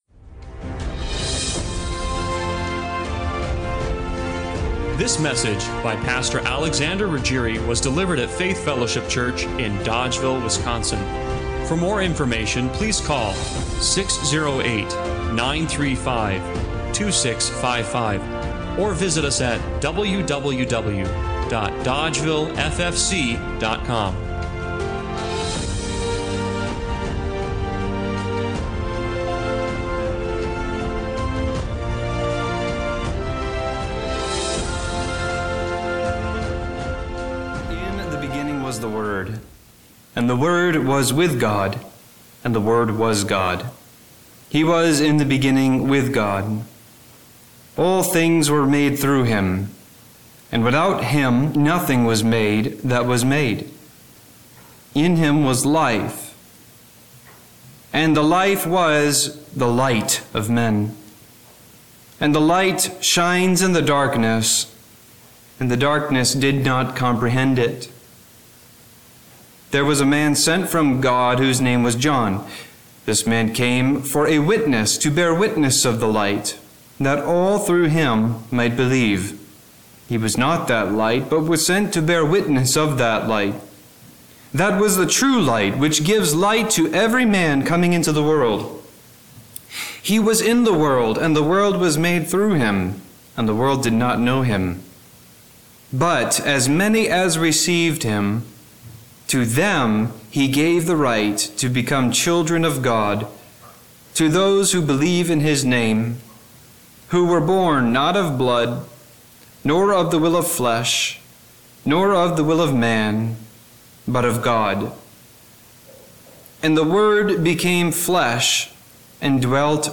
Service Type: Special Occasion